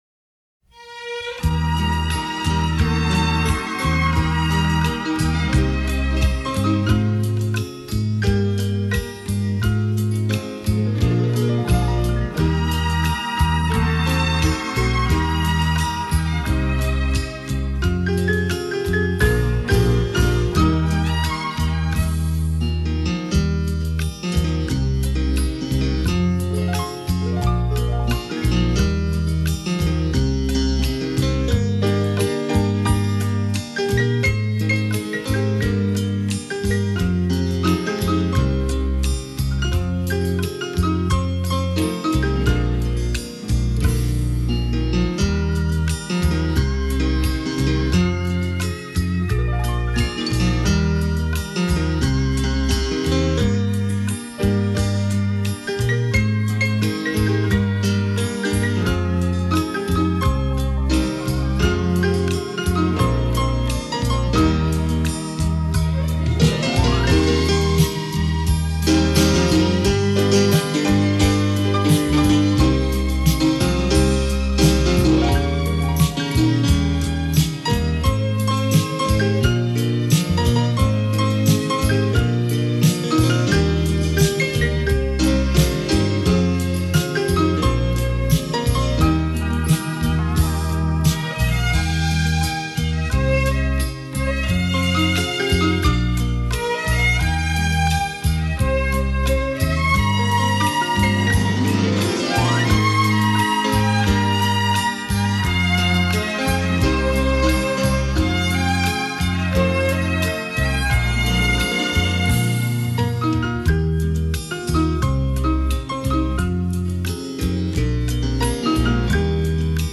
[15/1/2009]【首发】浪漫的双钢琴10